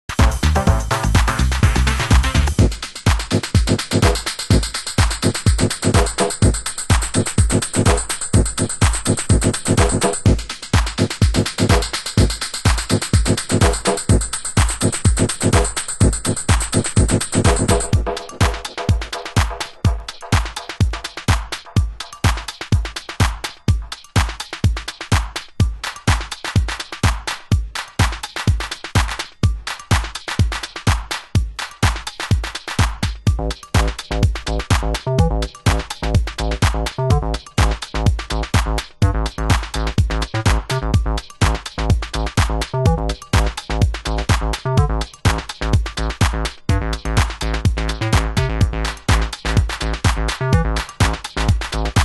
ヴィンテージテイストのビートが緩急をつけて展開する、テクノ・アシッド！